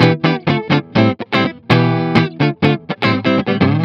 08 GuitarFunky Loop A.wav